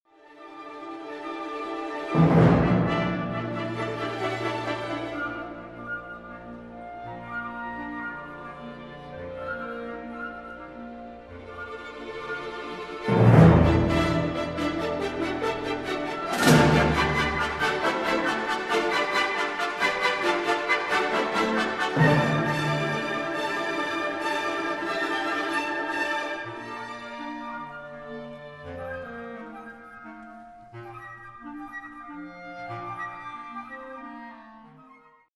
Het deed me denken aan de marktscene van het ballet Petrouchka op muziek van Igor Strawinsky. Je hoort daar flarden van marktgeluiden, een draaiorgel en nog andere dingen, die elkaar overlappen.